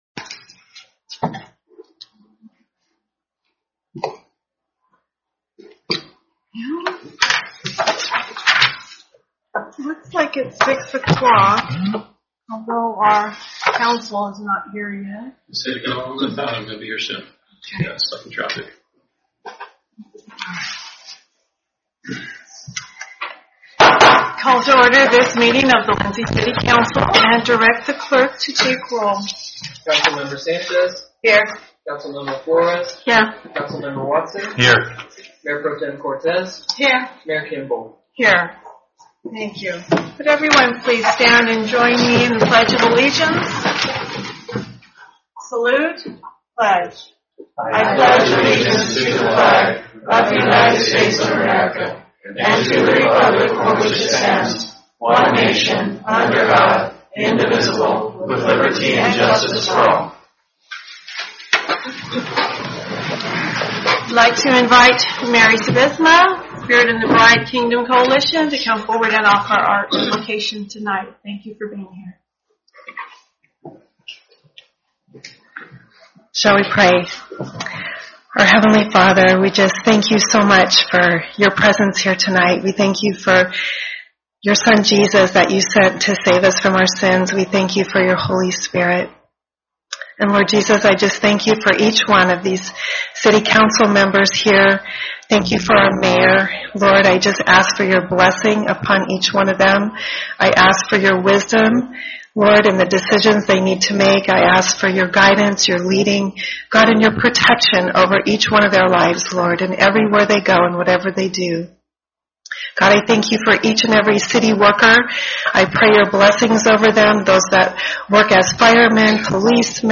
City Council Meeting